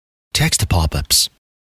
Text Pop-ups.wav